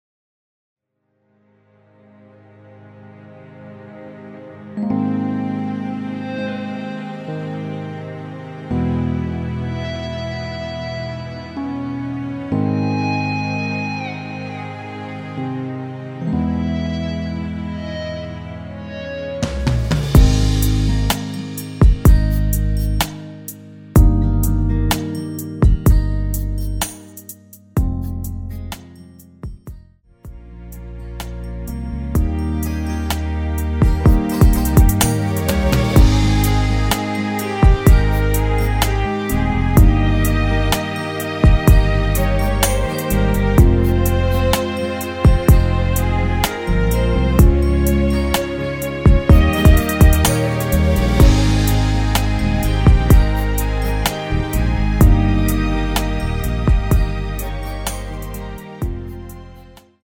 (-2) 내린 MR 입니다.(미리듣기 참조)
Ab
앞부분30초, 뒷부분30초씩 편집해서 올려 드리고 있습니다.